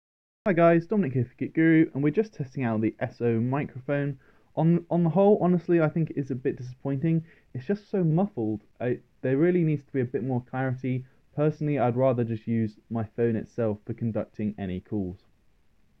Given the S0 comes with an ‘Clearwavz' mic, with a ‘high precision MEMS (Micro-Electrical-Mechanical System) silicon microphone', it is only fair we test that out.
That recording was taken with the S0 directly plugged into the Huawei MateBook X Pro, and honestly it is a bit disappointing.
If I was on the phone with someone using the S0, I'd constantly be asking them to repeat things as the sound is just quite muffled.
s0-mic.m4a